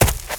STEPS Leaves, Run 22, Heavy Stomp.wav